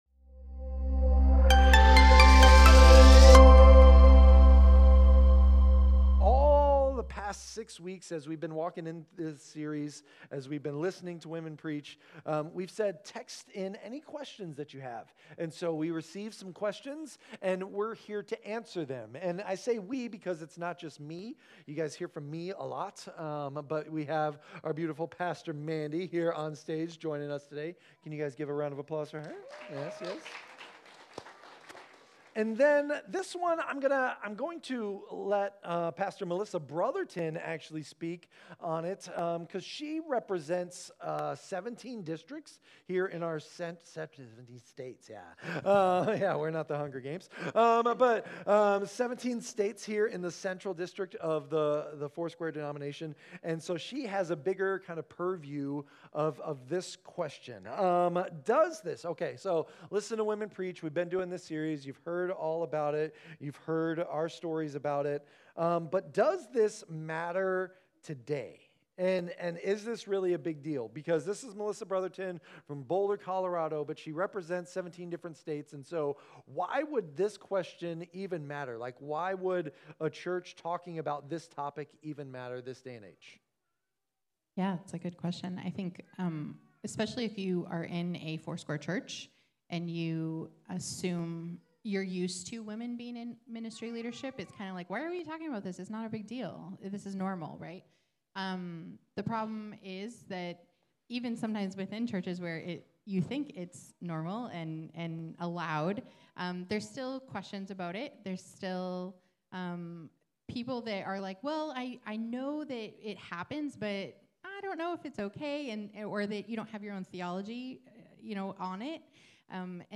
Sermon Notes
The panel will explore questions such as: